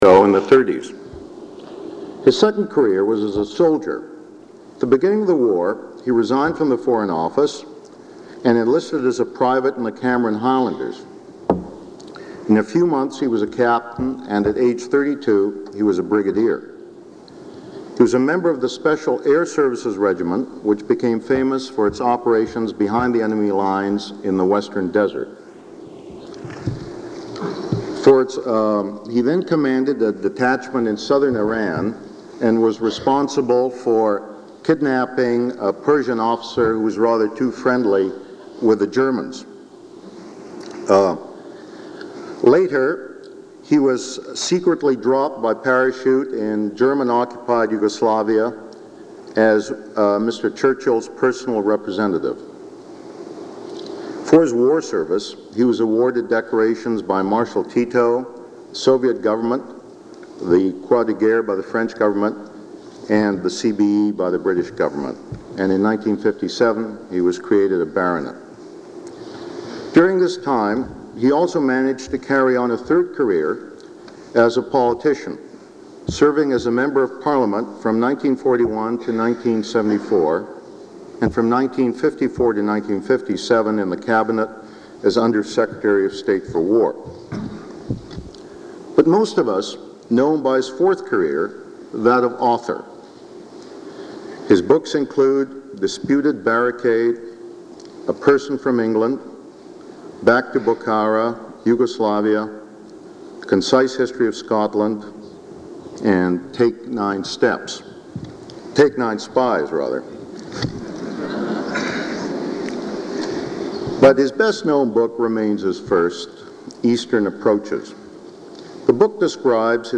Item consists of a digitized copy of an audio recording of a Vancouver Institute lecture given by Fitzroy Maclean on December 1, 1979.